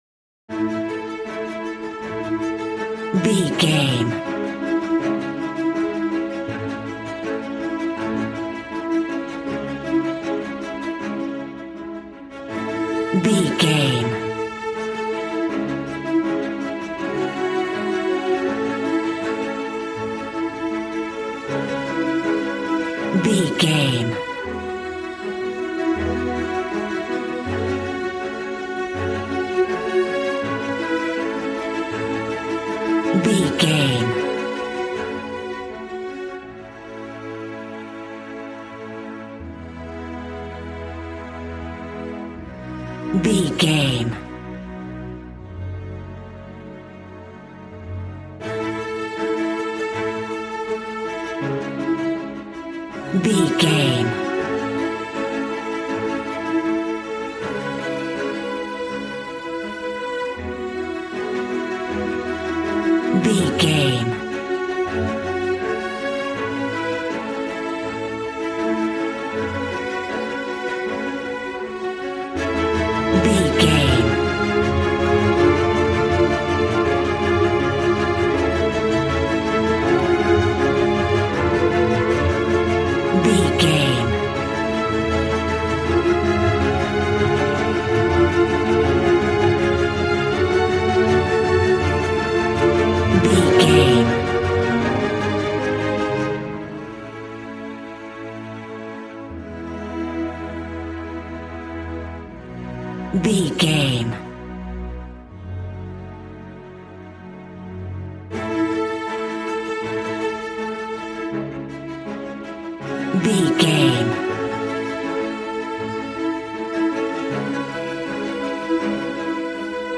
Regal and romantic, a classy piece of classical music.
Ionian/Major
Fast
regal
strings
brass